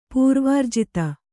♪ pūrvārjita